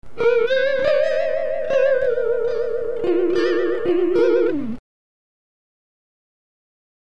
Oscillator = sine
Oscillator Freq = linearly increasing
Delay time = 20 ms
Depth = 30 ms
Wet Gain = 1
Add Chorus
clip16_6s_chorus3.mp3